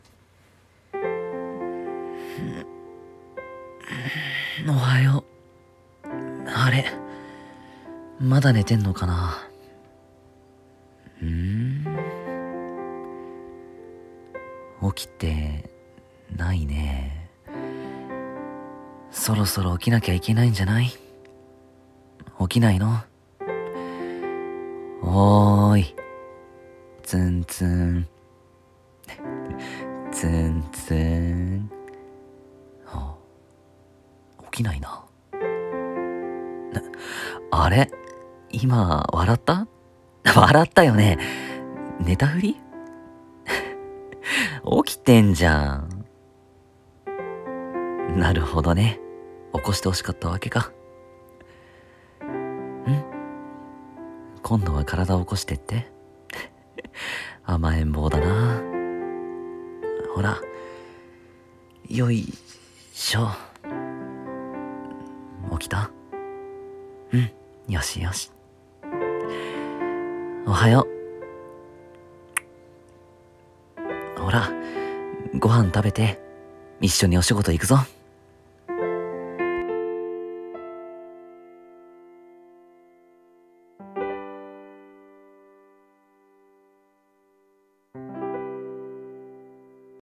【コラボ待ち/声劇】起きた？ツンツン（男性用）